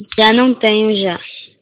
Si? Pois semella que o teu coñecemento do portugués non é nulo...as gravacións son de persoas portuguesas e brasileiras, achas algunha diferenza?